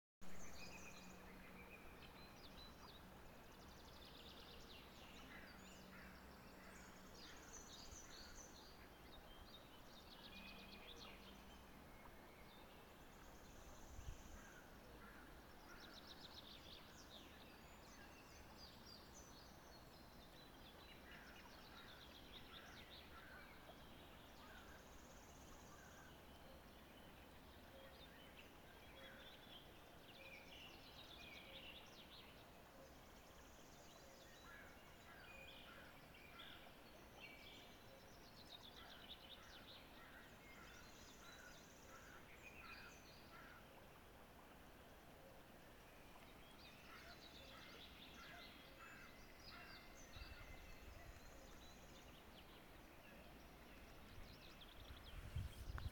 Putns (nenoteikts), Aves sp.
СтатусСлышен голос, крики